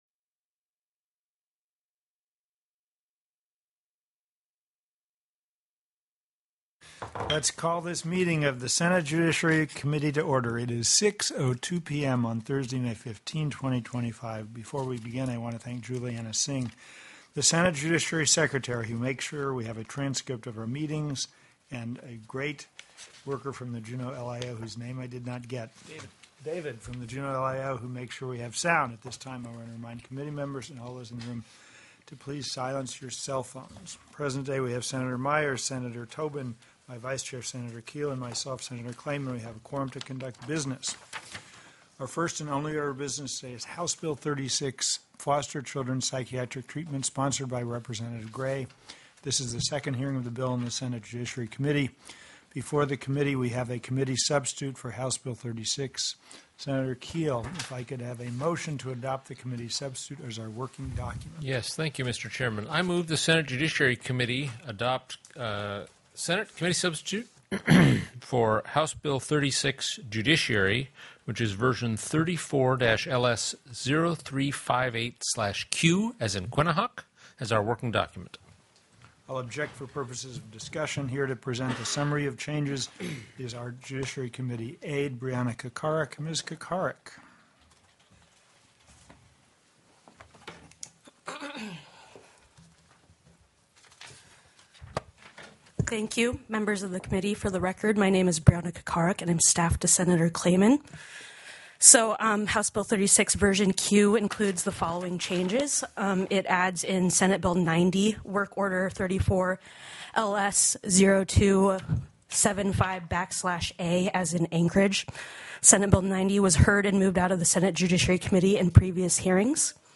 The audio recordings are captured by our records offices as the official record of the meeting and will have more accurate timestamps.
+= HB 36 FOSTER CHILDREN PSYCHIATRIC TREATMENT TELECONFERENCED